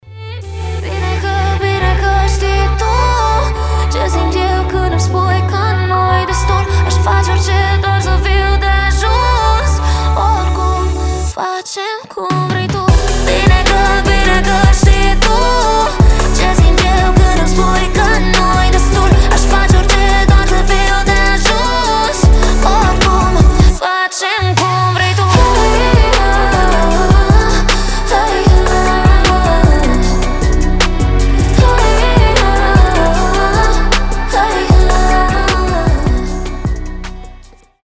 поп
красивый женский голос